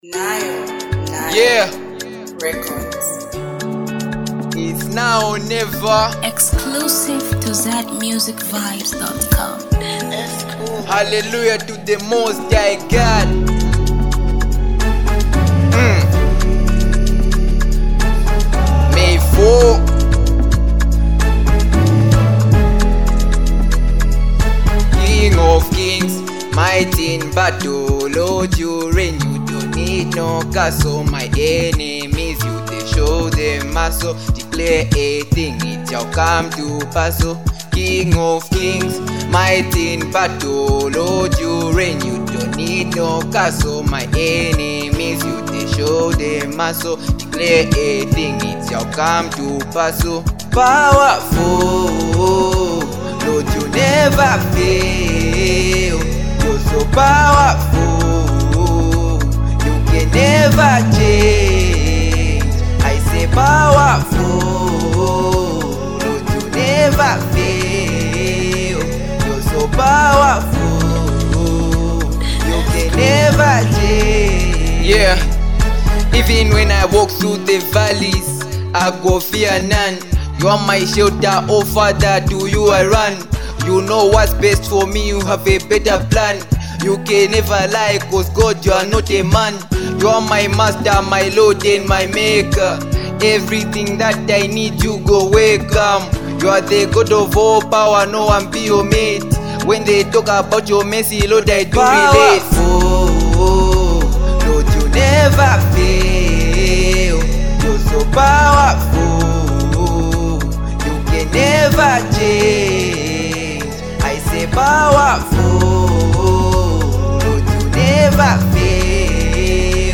afro Pop
gospel afro Masterpiece